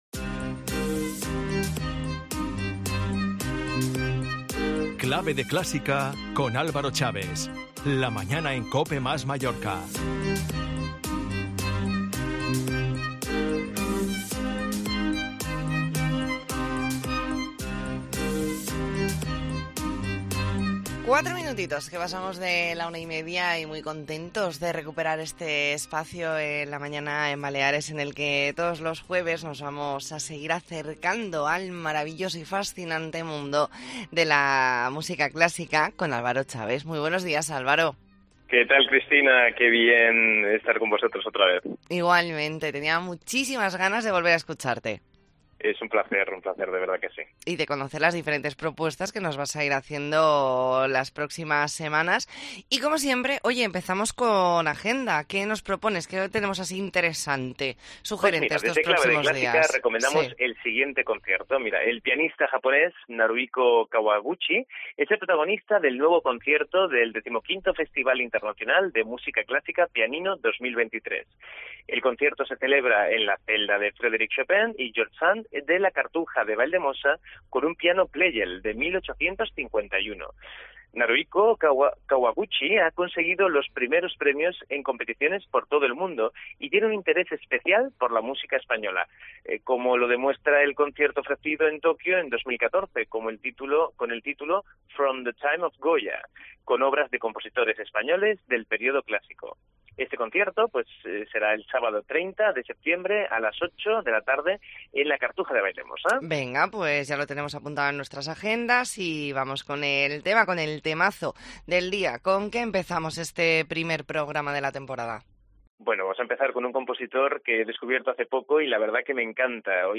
Hoy descubrimos al compositor italiano Antonio Lotti y escuchamos de sus piezas más significativas.